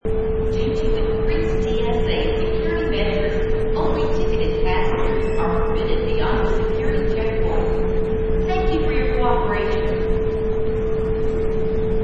Royalty free sound: Airport Security Announcment 2
Secuirty announcement in airport
Product Info: 48k 24bit Stereo
Category: Ambiences/Backgrounds / Airports and Airplanes
Relevant for: airport, airports, people, talking, background, planes, security, lounge, terminal, hallway, .
Try preview above (pink tone added for copyright).
Airport_Security_Announcment_2.mp3